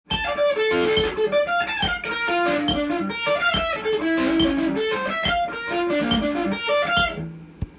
ほうきで掃くように　一定方向に弾いていく弾き方です。
②のフレーズは①のフレーズの逆バージョンになっています。
１弦から始まるのでアップピッキングから弾き始めて